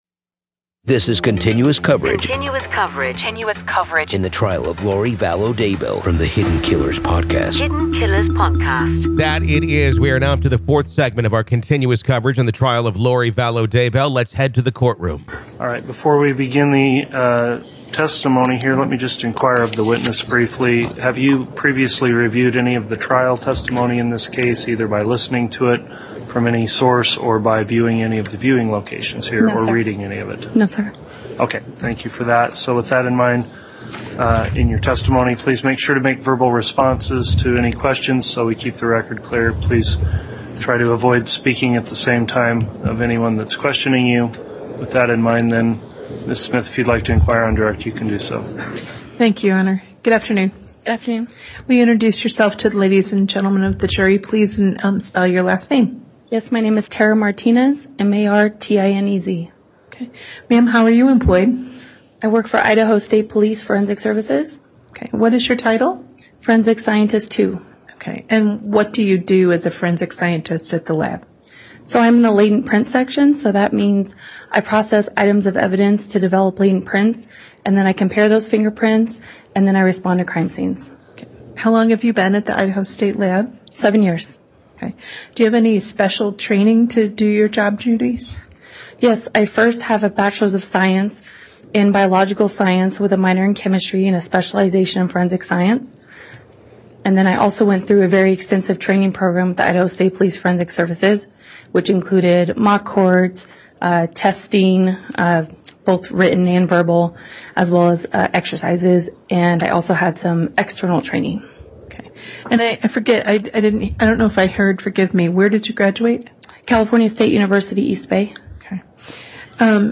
The Trial Of Lori Vallow Daybell Day 12 Part 4| Raw Courtroom Audio